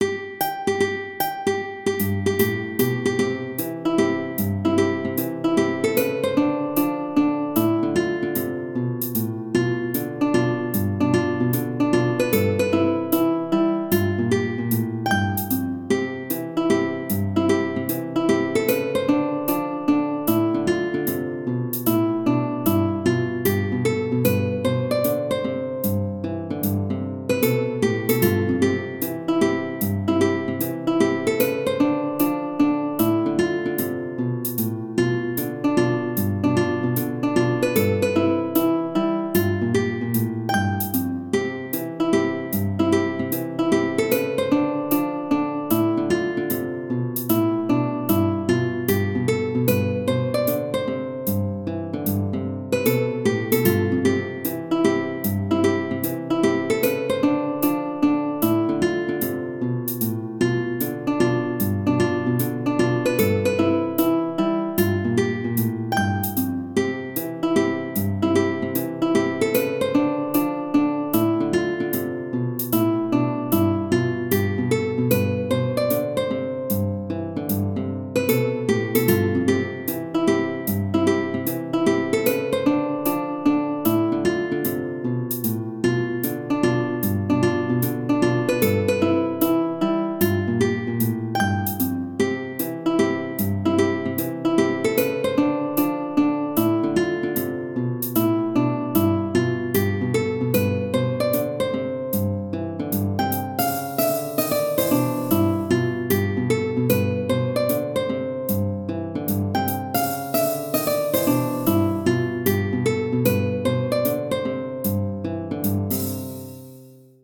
アコースティックギターが穏やかな春の日差しを受けて思わず歌い出します。